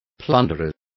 Complete with pronunciation of the translation of plunderers.